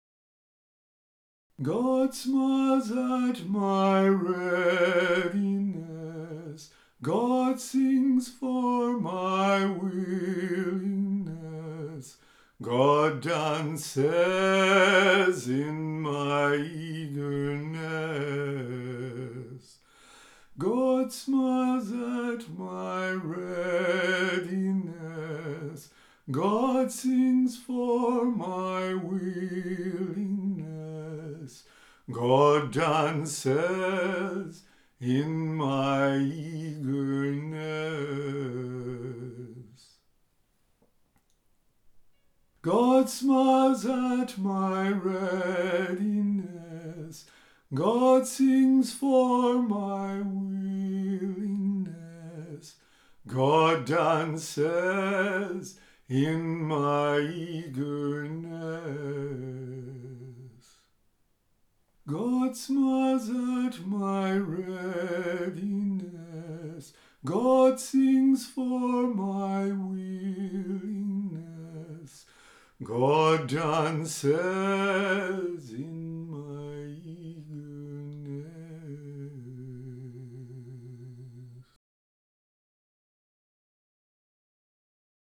Music for meditation and relaxation.